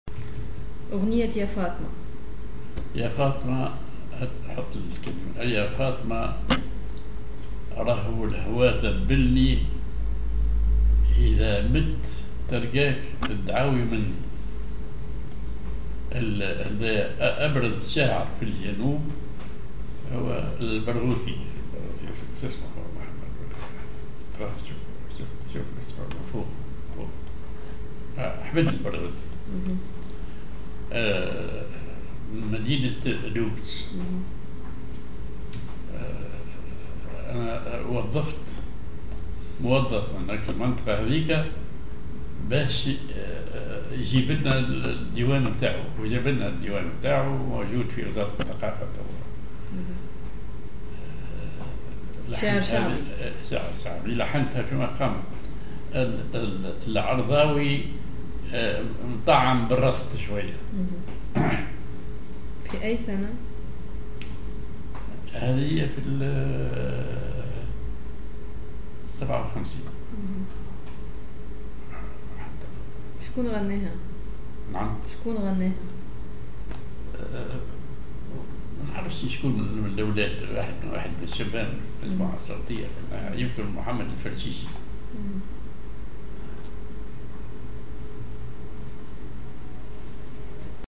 Maqam ar نوا
genre أغنية